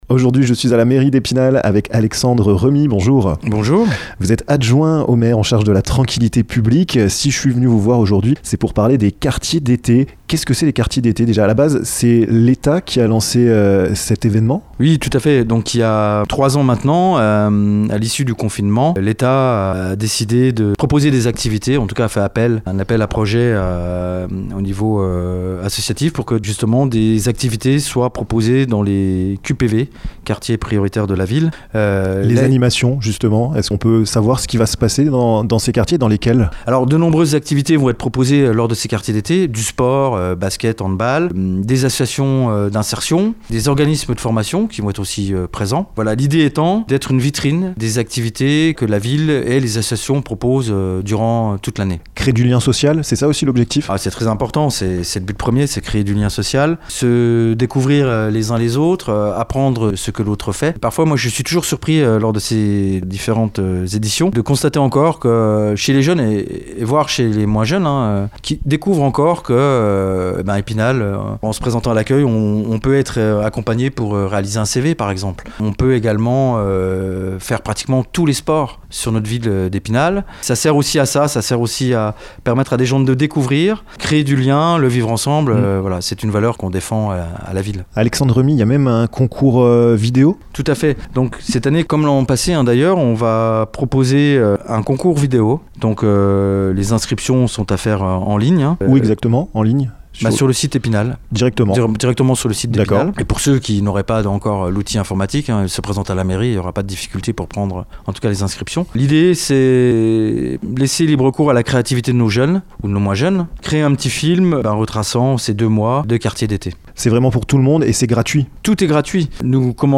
Des rencontres et des renforcements sociaux, du sport, un concours vidéo, des animations,... Alexandre REMY, adjoint au maire en charge de la tranquilité publique de la ville d'Epinal, vous invite aux quartiers d'été qui débutent ce mercredi 6 juillet !